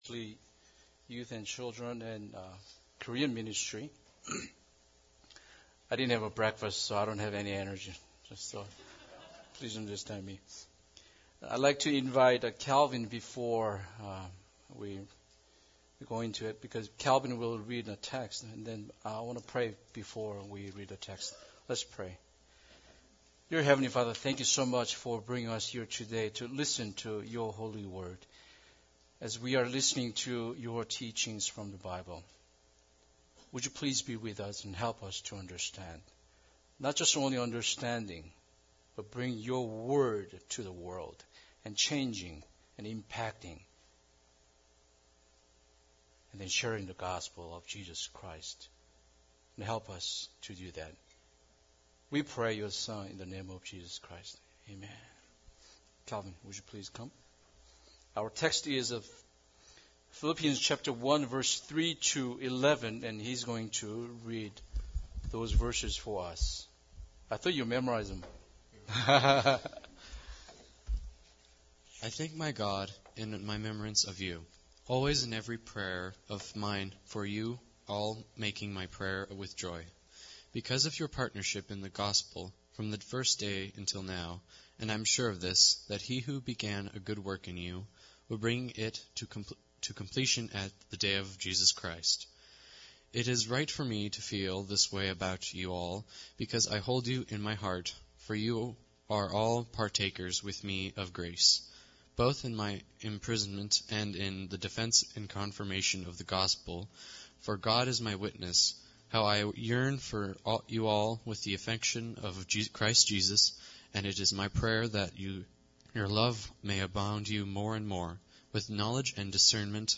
Philippians 1:3-11 Service Type: Sunday Service Bible Text